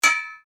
repair.wav